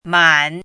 chinese-voice - 汉字语音库
man3.mp3